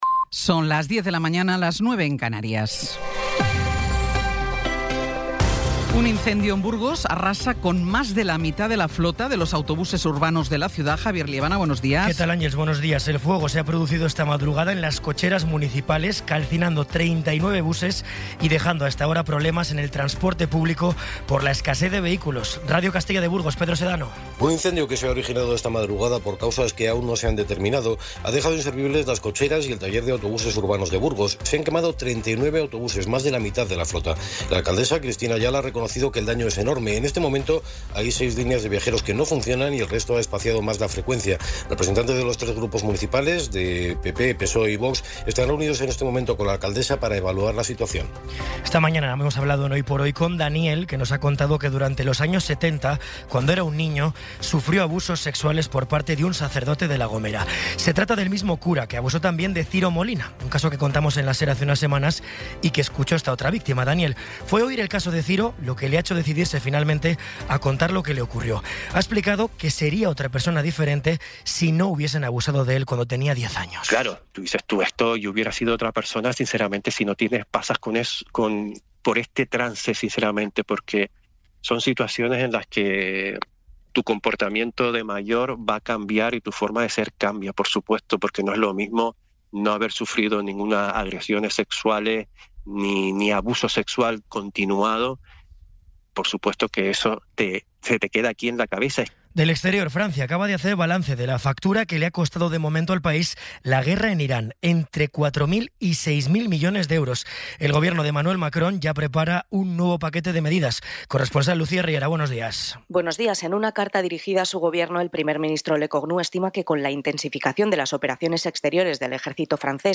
Resumen informativo con las noticias más destacadas del 21 de abril de 2026 a las diez de la mañana.